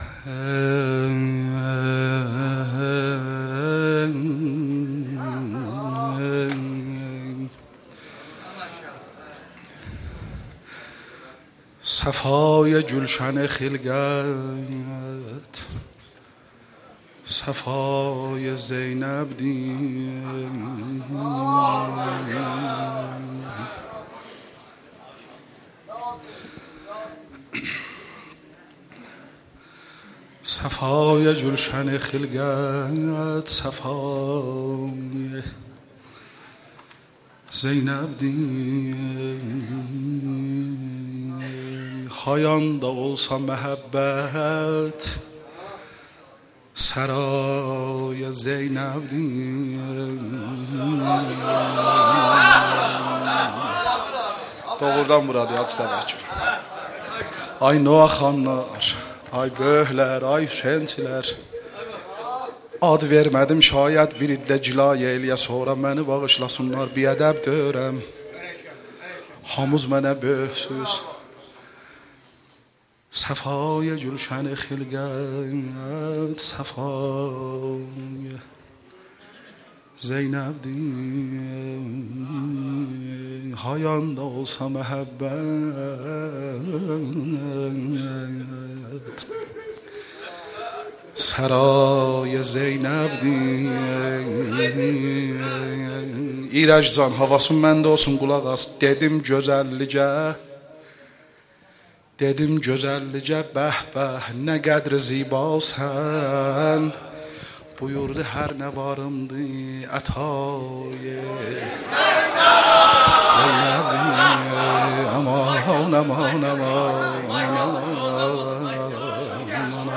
مولودی ترکی میلاد حضرت زینب کبری سلام الله علیها